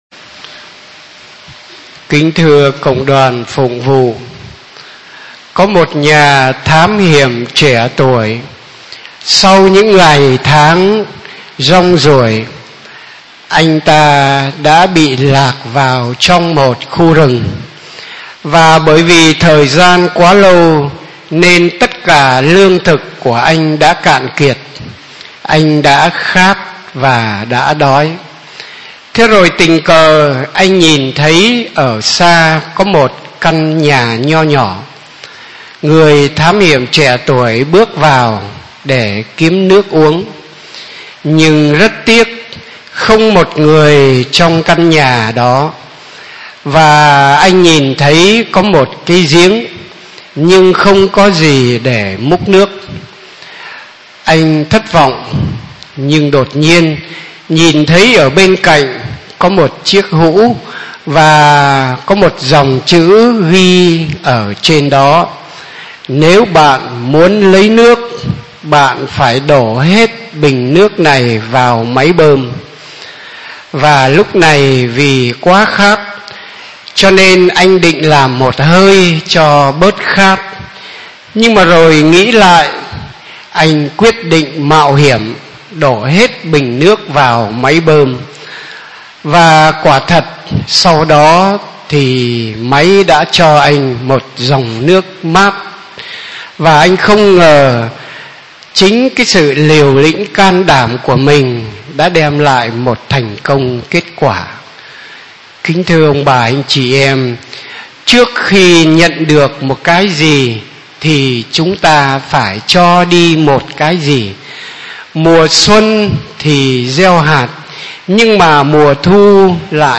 Bai Giang Le Khan Tron Cua Mot So Anh Doi XVII.mp3